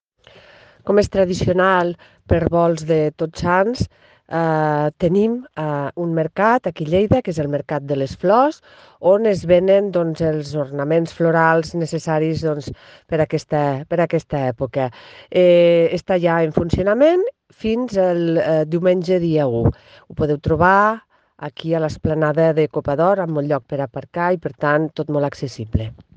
Tall de veu de la regidora Marta Gispert
tall-de-veu-de-la-regidora-marta-gispert